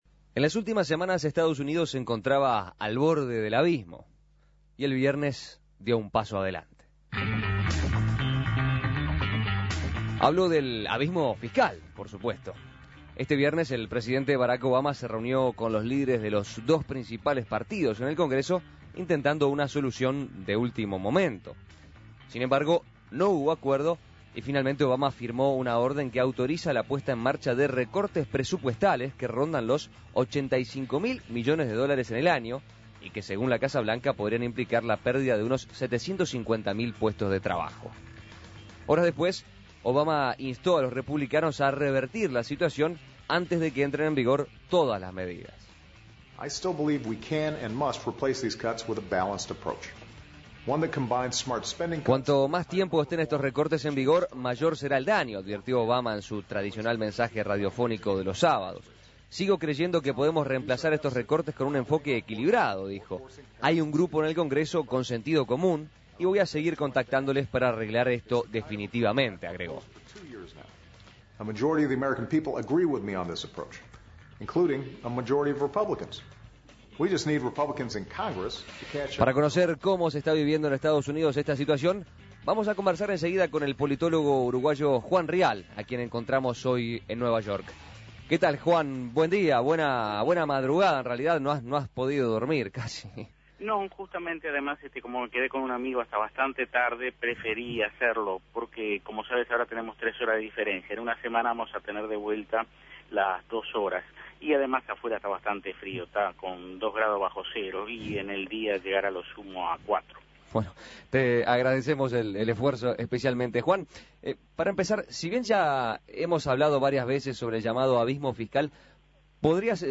desde Nueva York